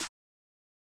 BZ Redd Snare 2.wav